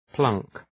Προφορά
{plʌŋk}